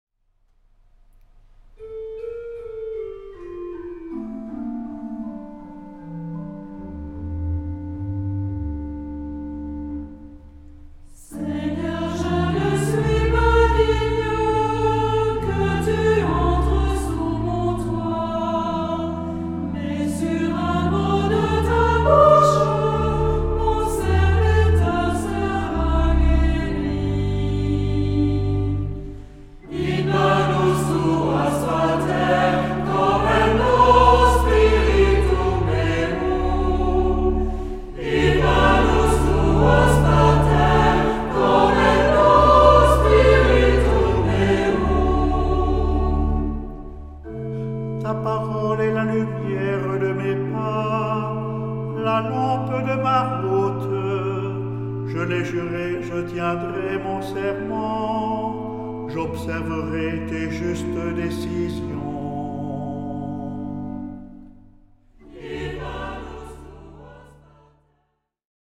Genre-Style-Forme : Tropaire ; Psalmodie
Caractère de la pièce : recueilli
Type de choeur : SAH  (3 voix mixtes )
Instruments : Orgue (1) ; Instrument mélodique (ad lib)
Tonalité : ré mineur ; sol mineur